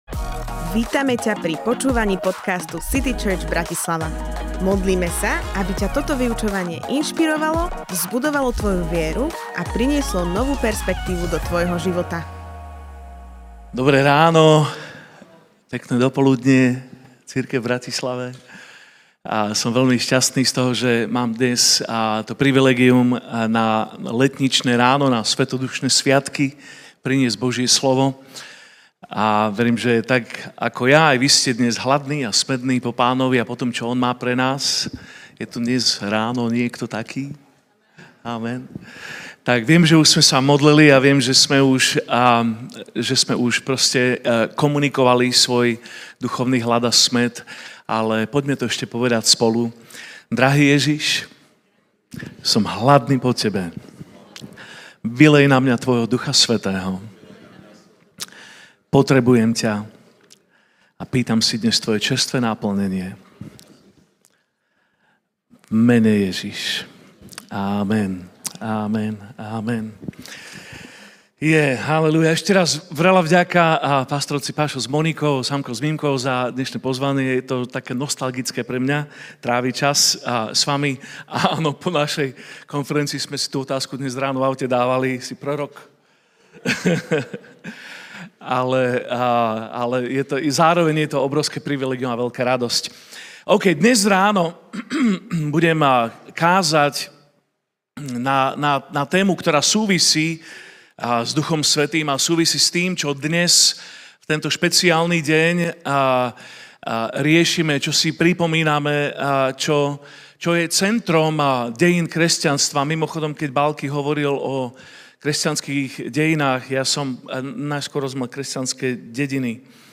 Moja sloboda Kázeň týždňa Zo série kázní